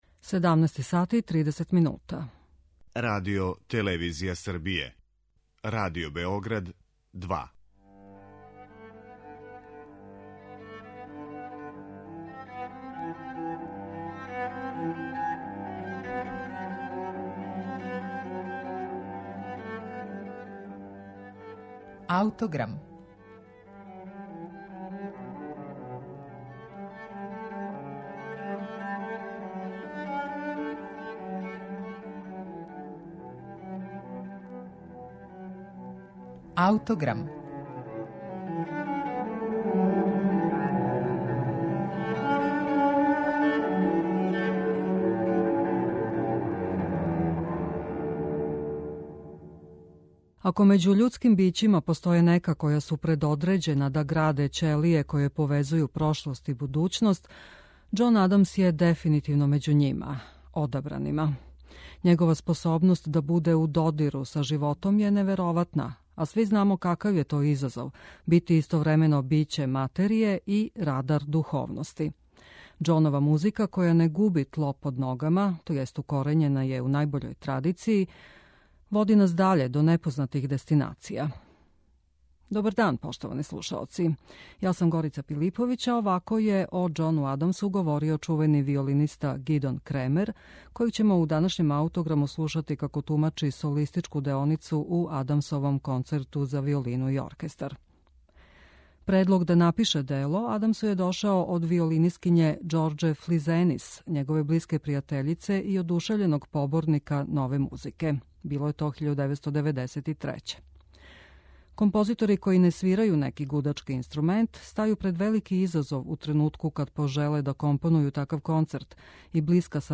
концерт за виолину и оркестар